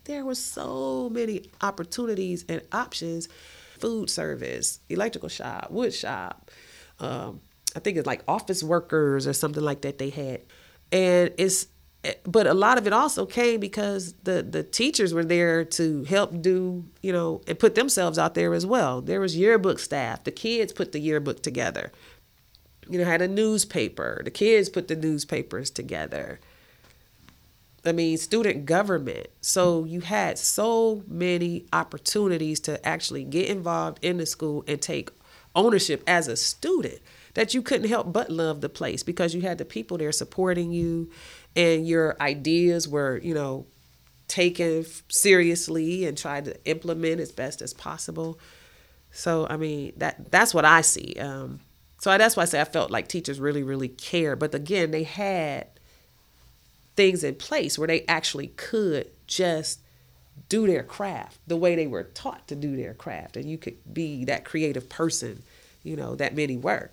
Collinwood High School Oral History Project.